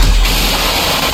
Blitzjumpscare.mp3